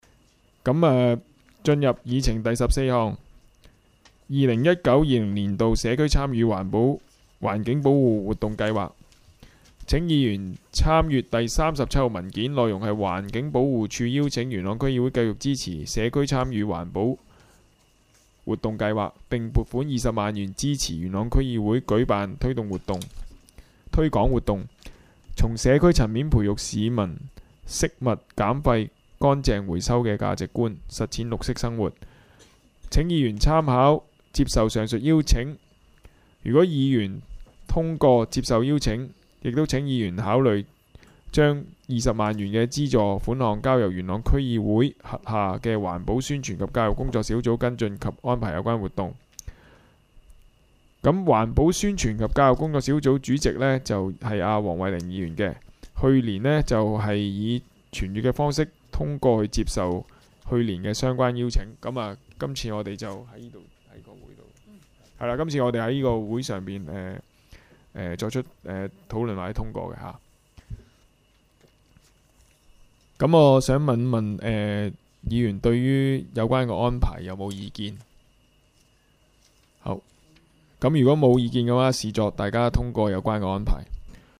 区议会大会的录音记录
元朗区议会第二次会议
地点: 元朗桥乐坊2号元朗政府合署十三楼会议厅